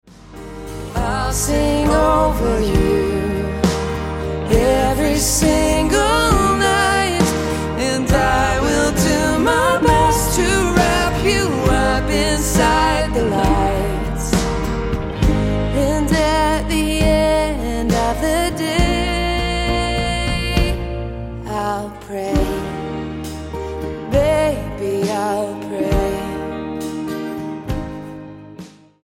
STYLE: Country